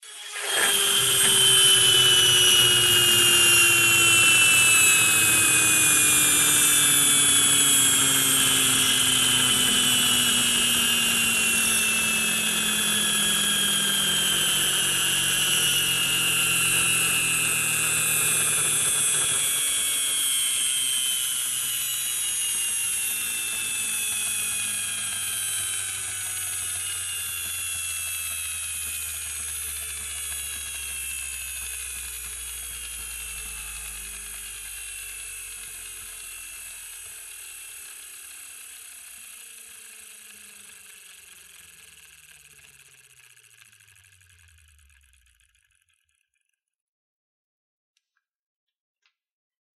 toy spring airplane motor two.mp3
Recorded with a Steinberg Sterling Audio ST66 Tube, in a small apartment studio.
.WAV .MP3 .OGG 0:00 / 0:50 Type Mp3 Duration 0:50 Size 8,38 MB Samplerate 44100 Hz Bitrate 96 kbps Channels Stereo Recorded with a Steinberg Sterling Audio ST66 Tube, in a small apartment studio.
toy_spring_airplane_motor_two_dv8.ogg